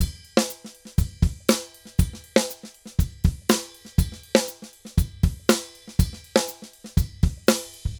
Drums_Candombe 120_4.wav